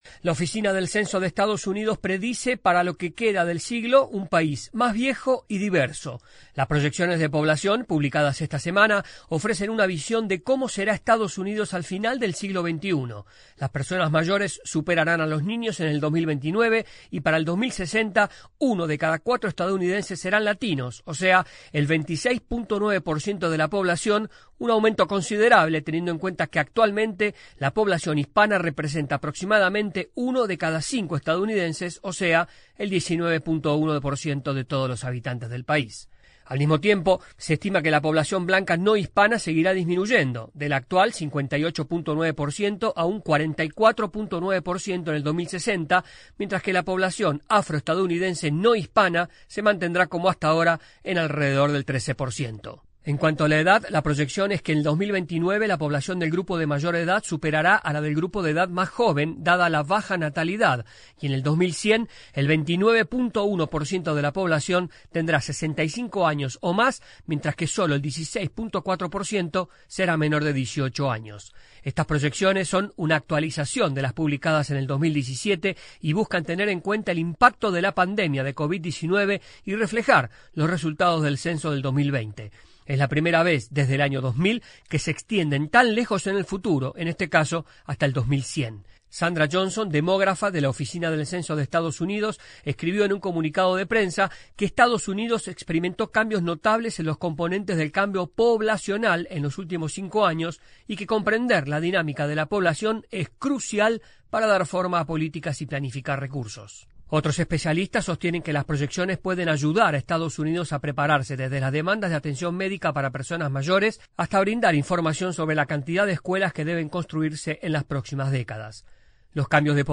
AudioNoticias
desde la Voz de América en Washington DC.